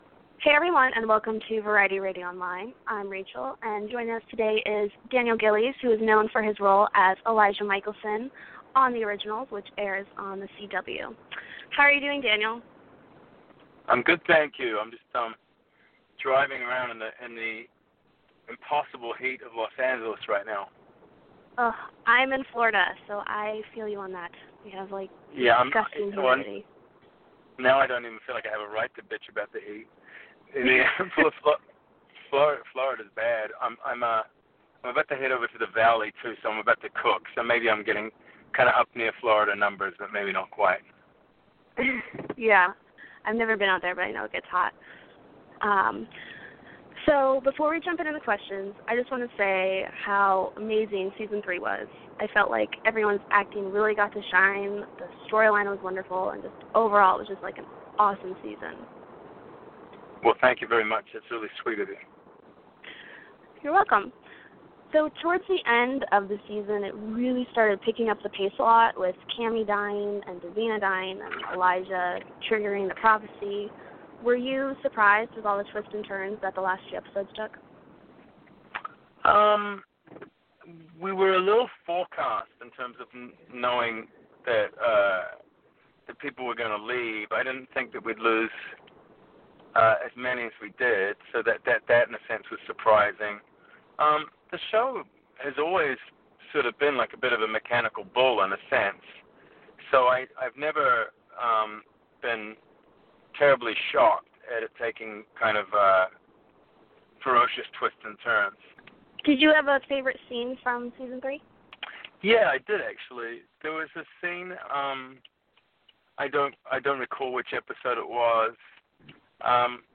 Daniel Gillies "The Originals" Interview